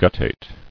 [gut·tate]